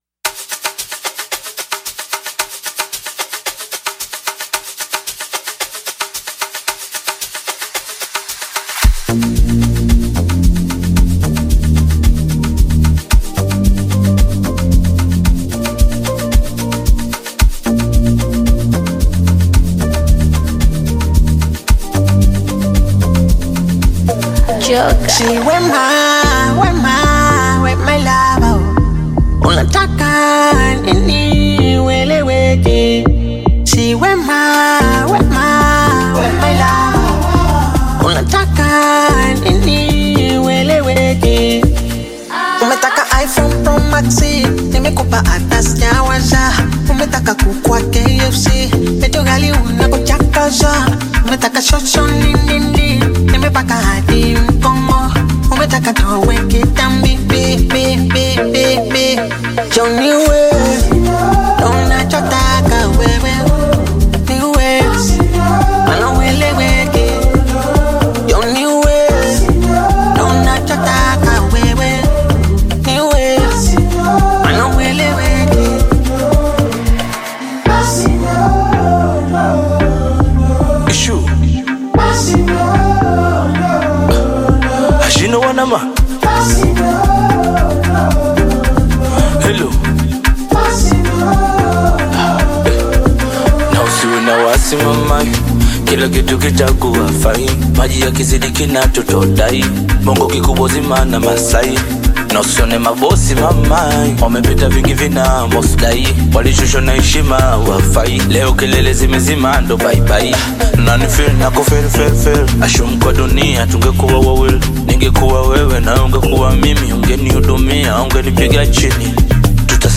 Bongo Flava music track
Tanzanian Bongo Flava artist, singer and songwriter